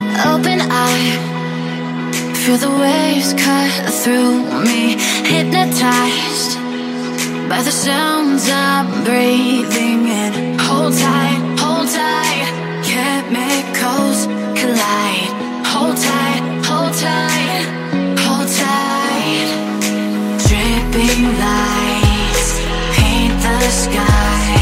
موسيقى غنائية عالية جدا